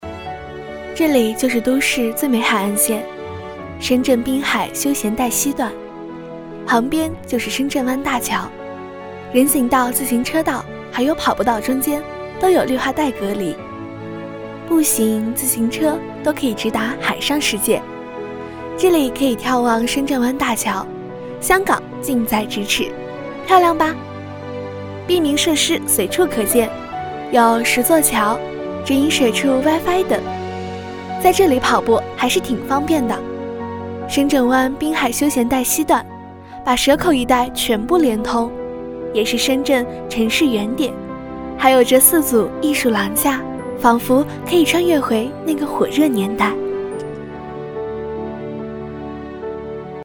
女配音-配音样音免费在线试听-第47页-深度配音网
女188-宣传片-深圳湾大桥
女188年轻自然 v188